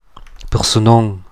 Ladinisch-mundartliche Form
[pəʀsəˈnɔŋ]
In der Aussprache ist die Grödner Variante angegeben.
Persenon_Mundart.mp3